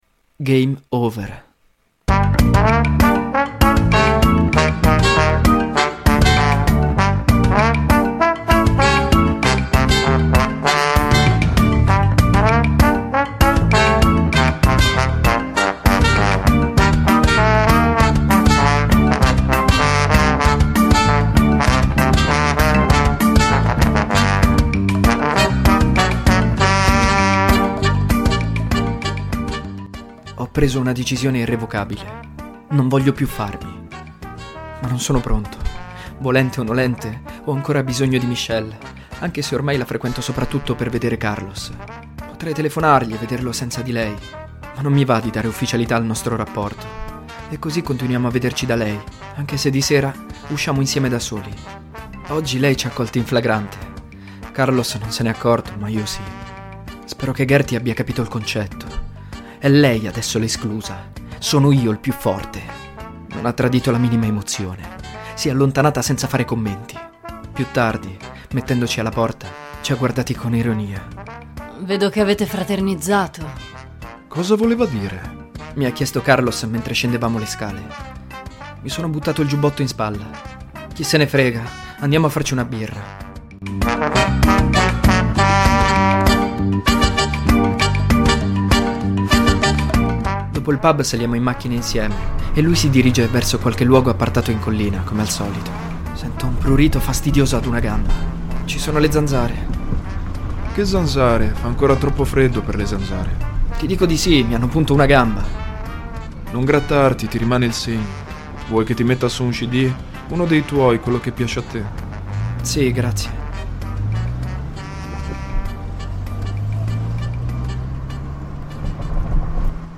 Durante l'episodio si possono ascoltare brani di Michael Land tratti da "The Secret of Monkey Island", "Gouge away" dei Pixies e una cover di "I am" di Jamie Campbell Bower.
During the episode you can hear songs by Michael Land taken from "The Secret of Monkey Island", "Gouge away" by the Pixies and a cover of "I am" by Jamie Campbell Bower.